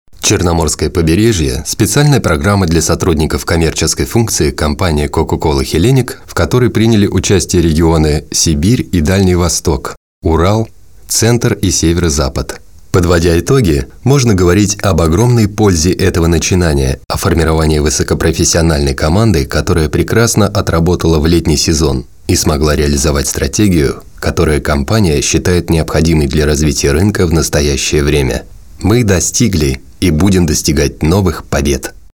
[COCA-COLA Hellenic] Отрывок начитки для корпоративного фильма Категория: Аудио/видео монтаж